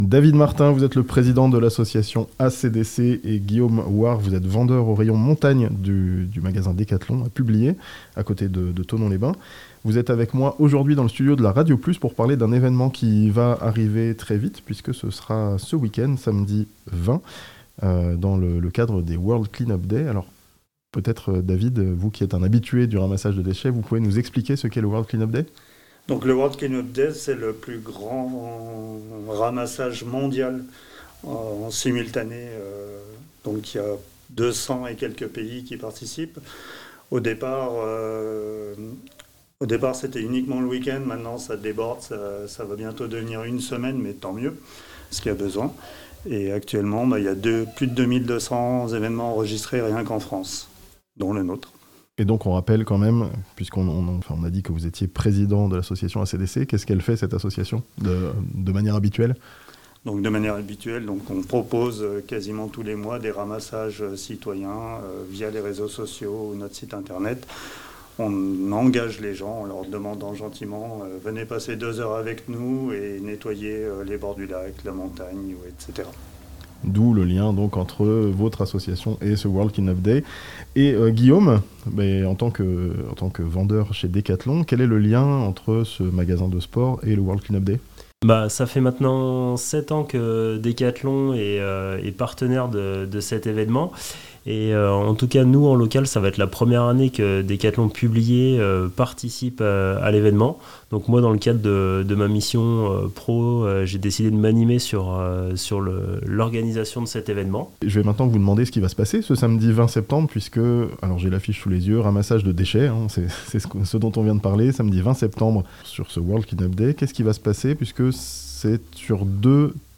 Un grand ramassage de déchets prévu dans le Chablais pour le World Clean Up Day (interview)